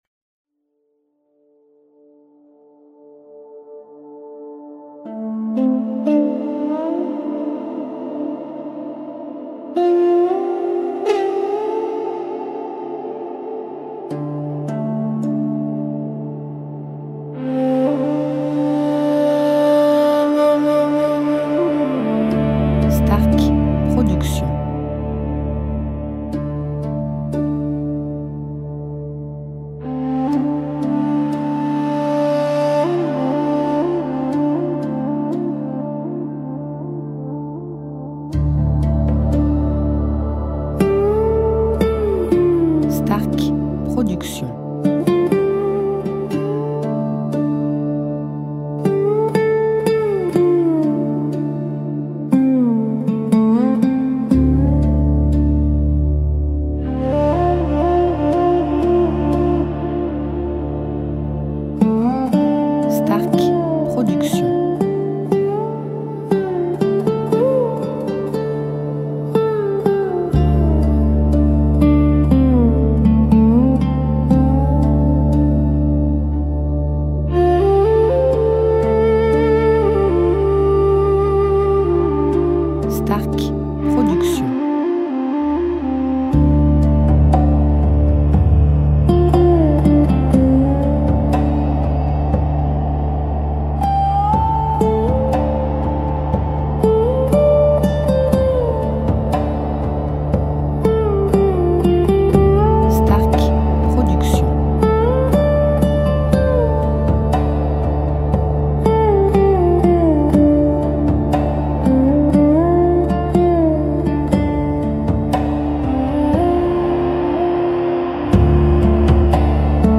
style Ayurvédique durée 1 heure